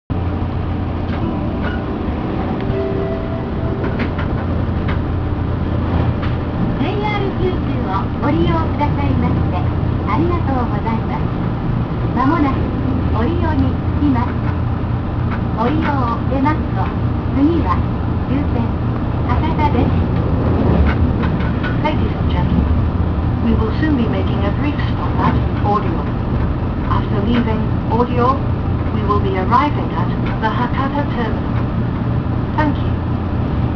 〜車両の音〜
・883系 特急「ソニック」車内放送
他の特急と変わらないごく普通の自動放送で、駅放送の女性と同じ方が担当されているようです。